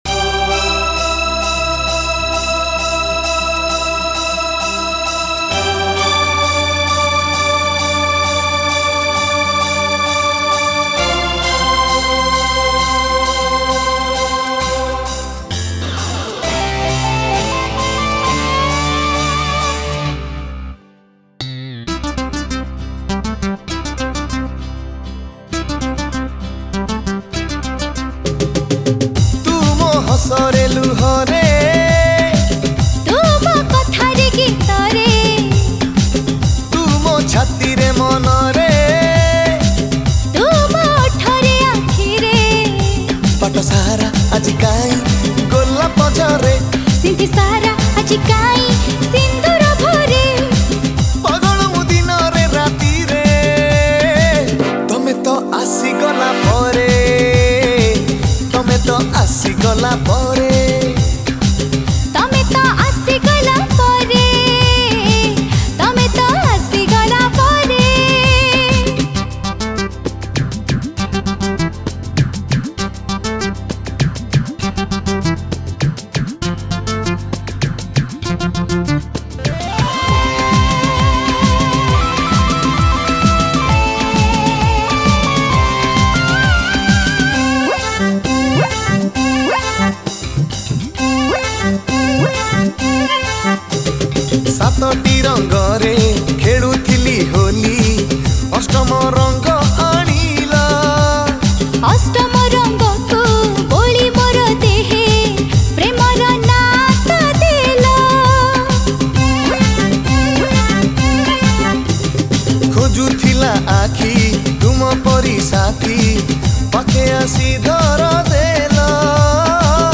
Odia Jatra Songs Download